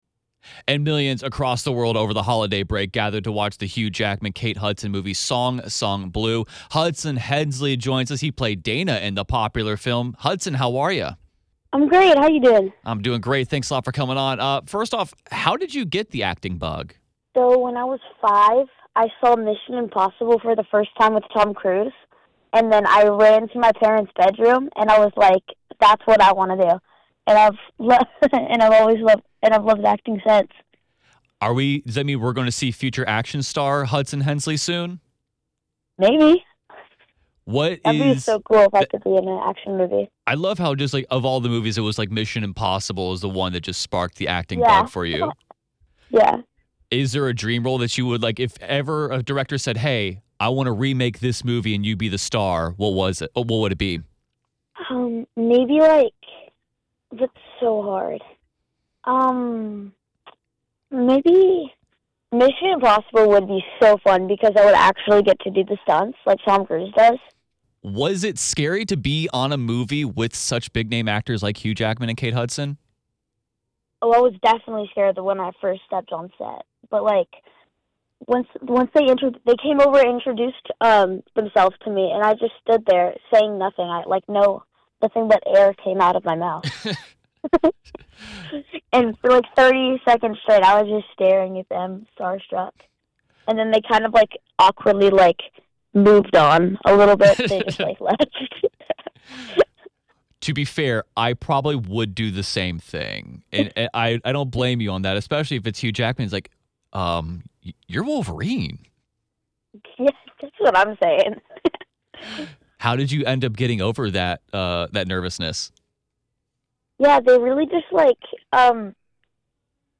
the full interview.